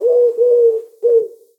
This audio file is a very short bird sound.